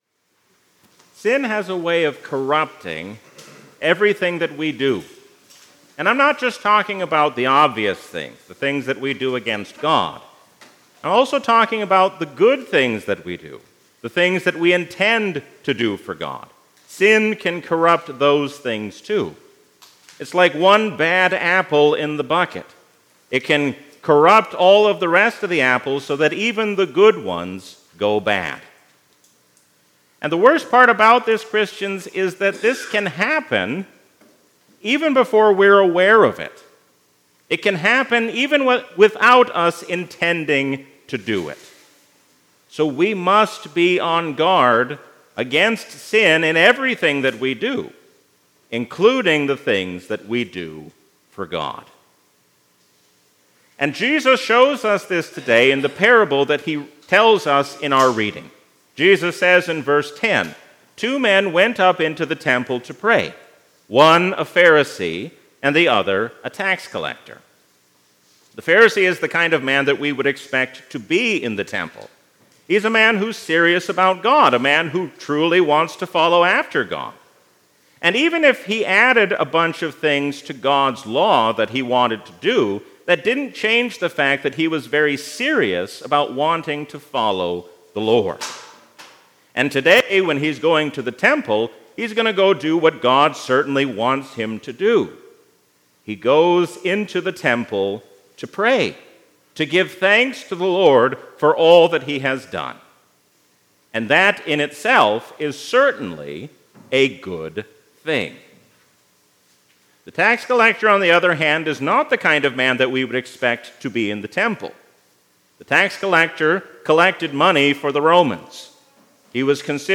A sermon from the season "Trinity 2023."